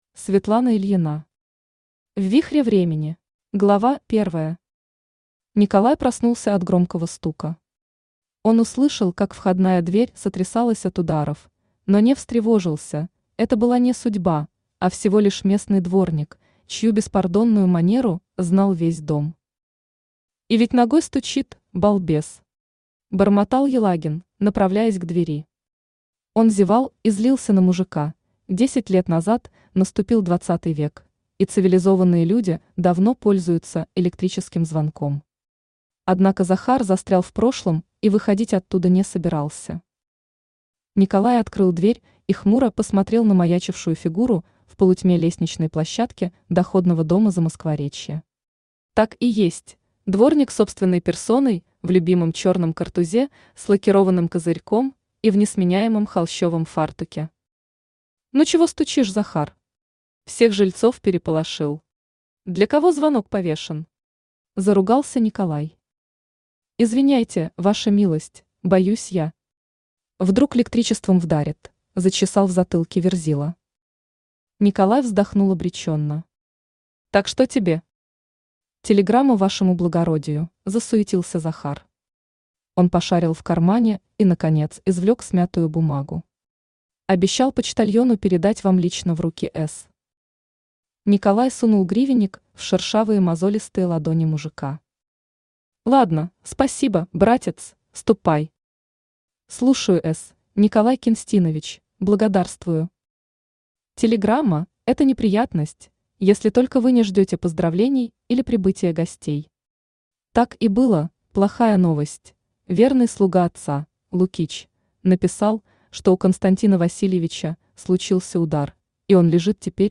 Аудиокнига В вихре времени | Библиотека аудиокниг
Aудиокнига В вихре времени Автор Светлана Викторовна Ильина Читает аудиокнигу Авточтец ЛитРес.